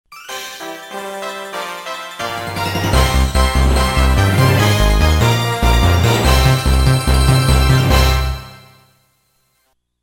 theme